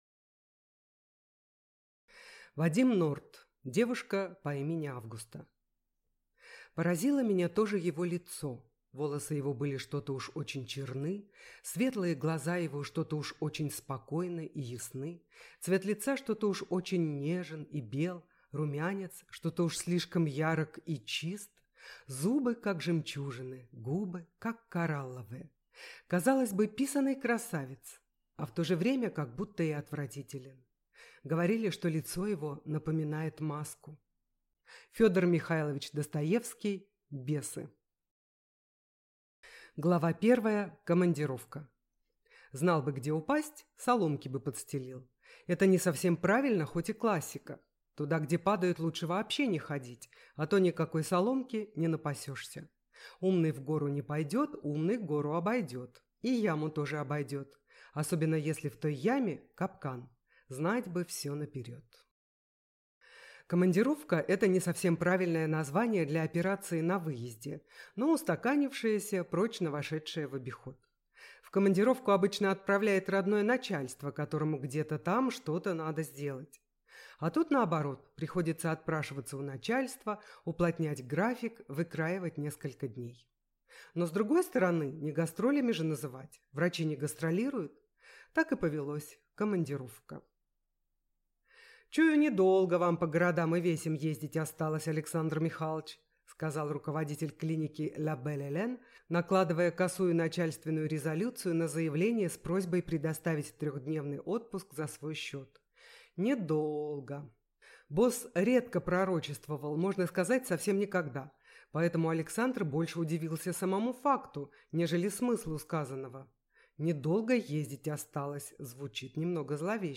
Аудиокнига Девушка по имени Августа | Библиотека аудиокниг